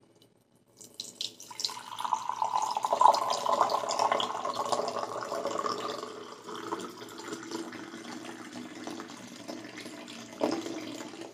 sound-of-water